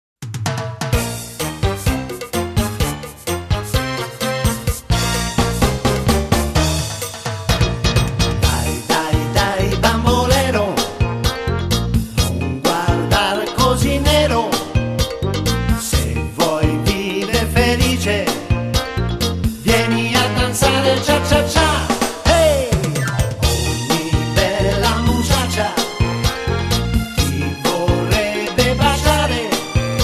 Beguine